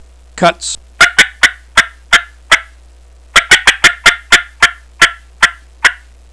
Yellow Yelper 3 Reed, 3 Cutt Mouth Call
Listen to 6 seconds of cutts
yythreethreecutts6.wav